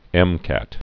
(ĕmkăt)